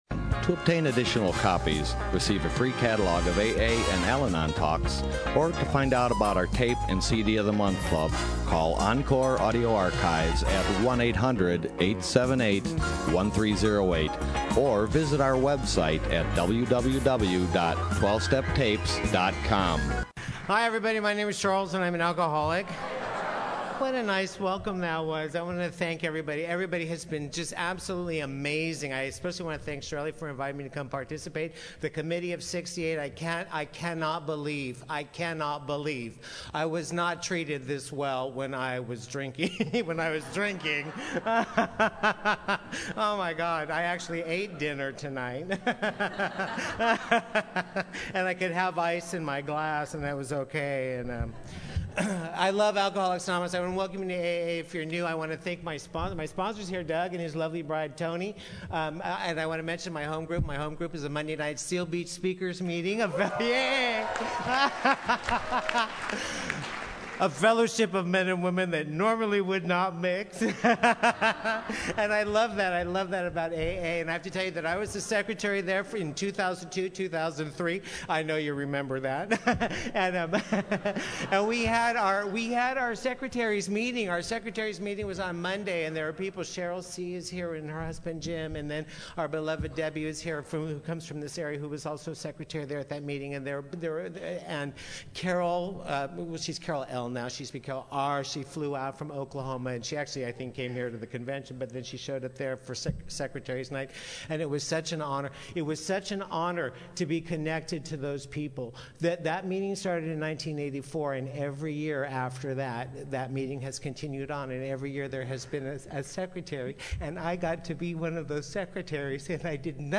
Desert POW WOW 2015